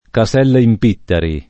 kaS$lla] s. f. — sim. il top., pers. m. stor. e cogn. Casella e il top. Caselle (es.: Caselle Lurani [kaS$lle lur#ni], Lomb.; Caselle in Pittari [
kaS$lle im p&ttari], Camp.)